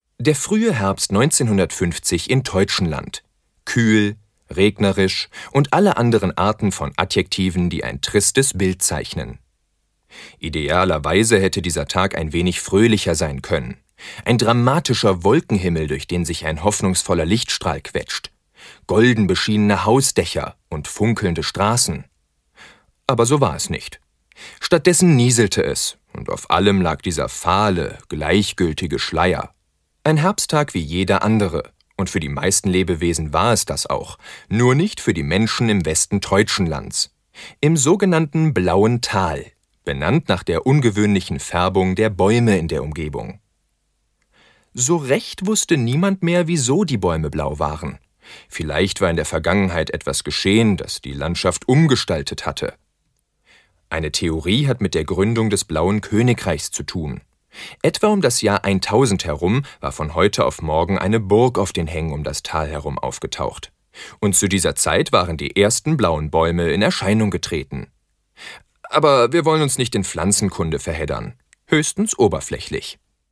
Sprachproben
Sprecher, Synchronsprecher